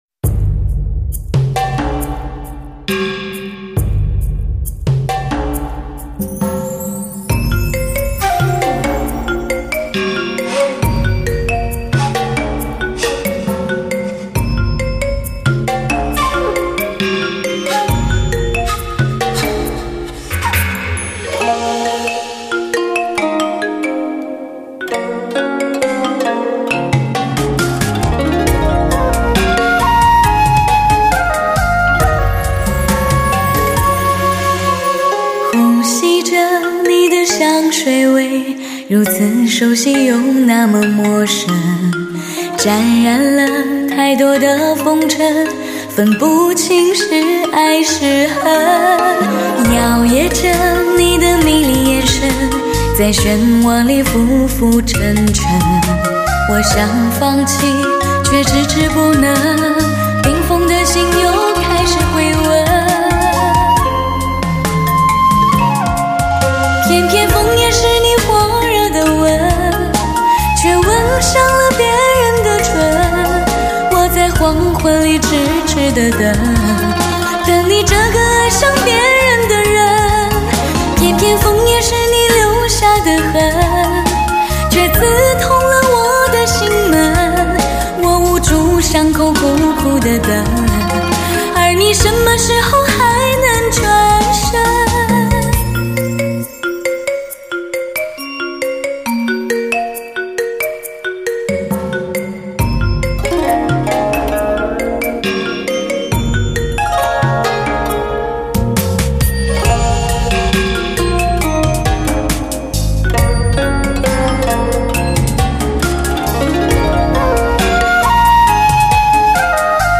唱片类型：汽车音乐
最孤独最寂寞的发烧女声，几多感怀着“知音少，弦断有谁听！”